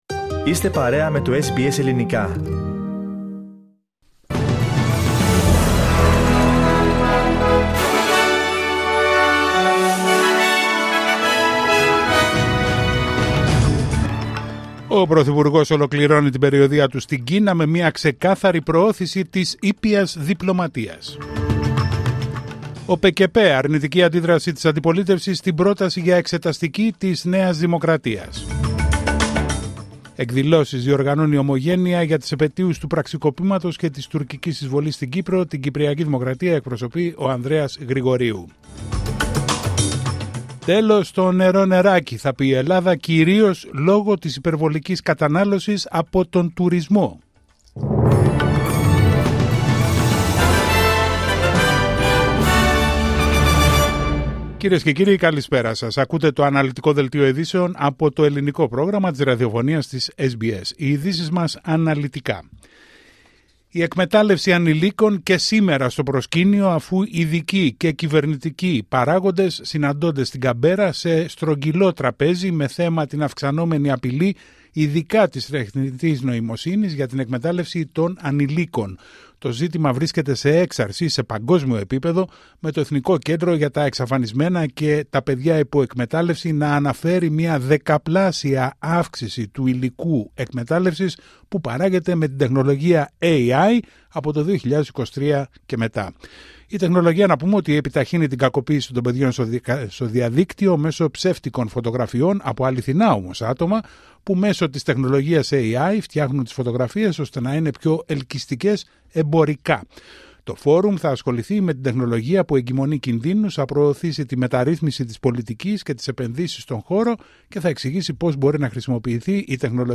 Δελτίο ειδήσεων Πέμπτη 17 Ιουλίου 2025